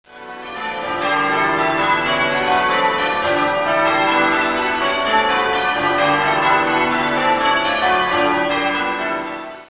Tewkesbury Abbey Bells
The old tenor certainly was a bit on the thin side, since the new tenor (27-1-5) is also in the key of D.